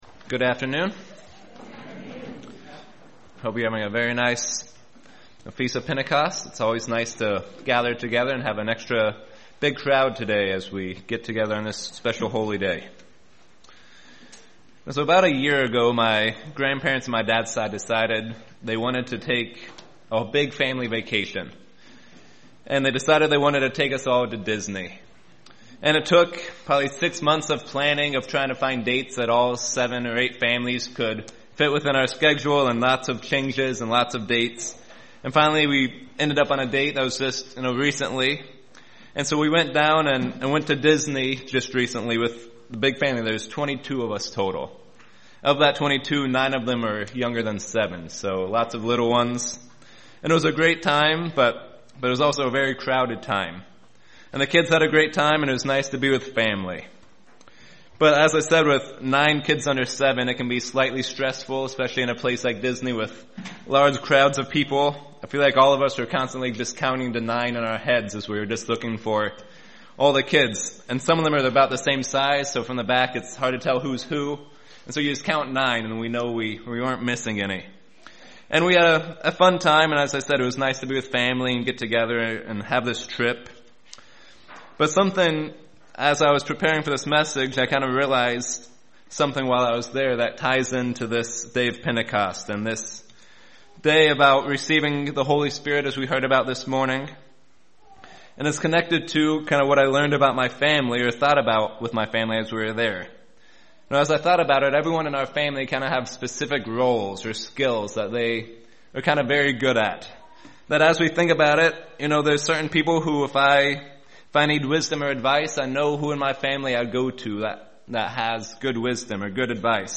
Sermons
Given in Lehigh Valley, PA